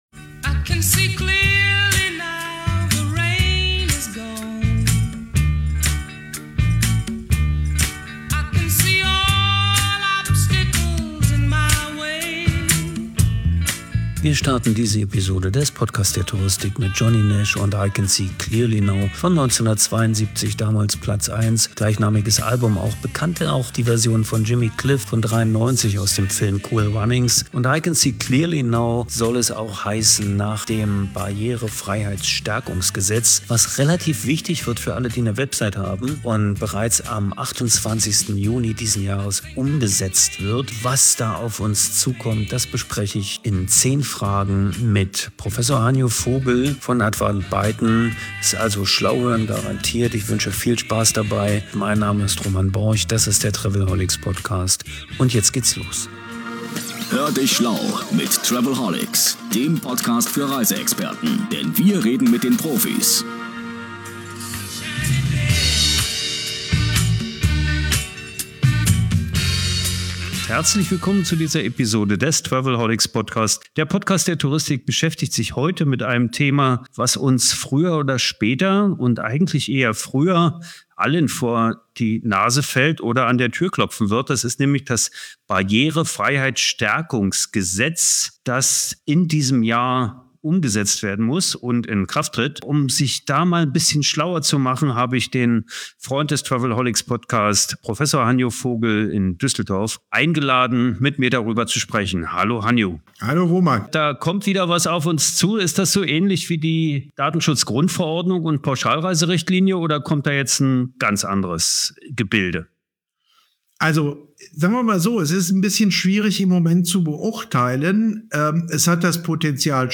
Damit ist unser Talk ein echtes „Must Hear“ für alle, die im digitalisierten B2C-Geschäft der Touristik tätig sind und sich frühzeitig wappnen wollen.